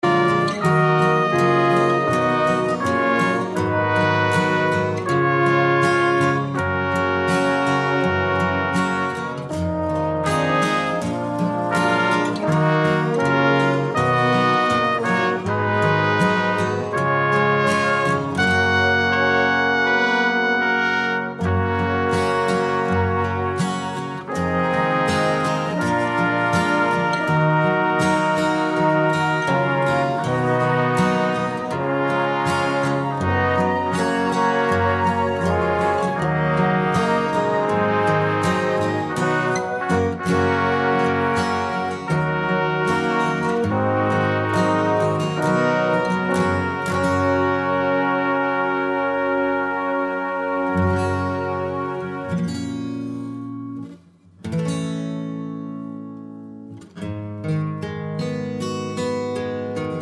Alternative End Backing Vocals Soundtracks 7:23 Buy £1.50